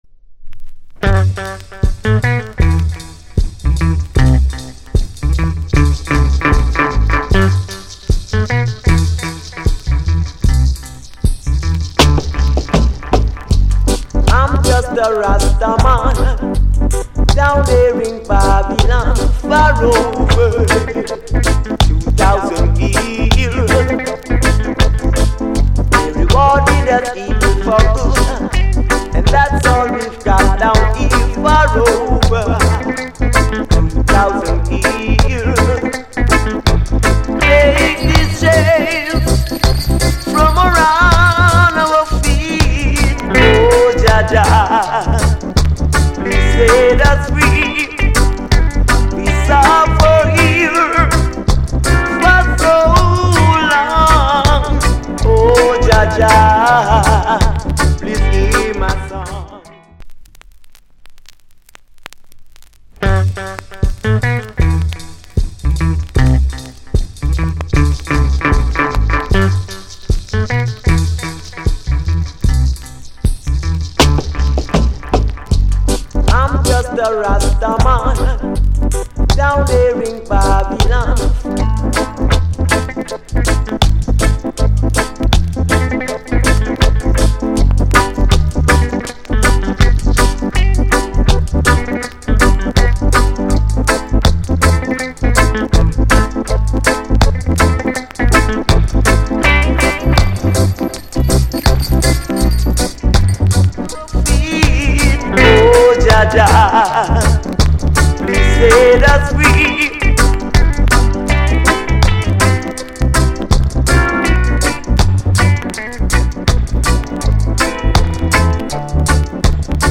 *目を瞑るとプリンス・アラーかなと間違うクリソツ、ラスタ・ルーツ・ロック。